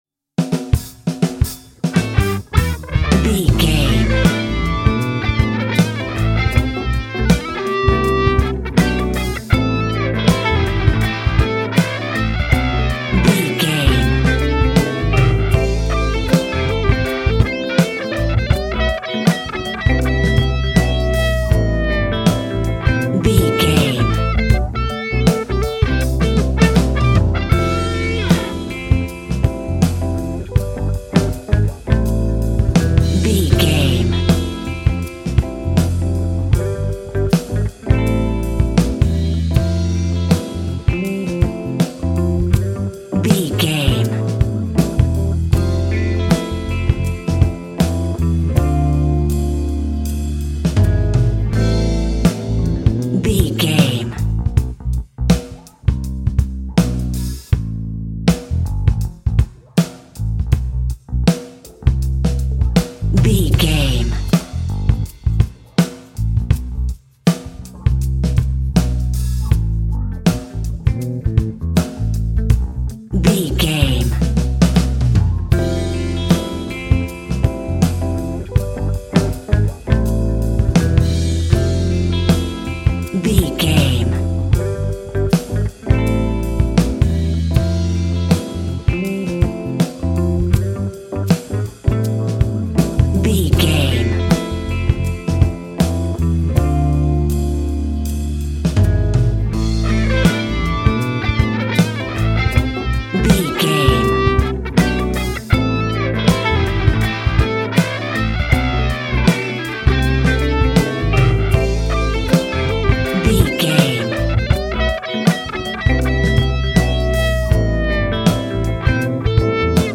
Epic / Action
Fast paced
In-crescendo
Uplifting
Ionian/Major
A♭
hip hop
instrumentals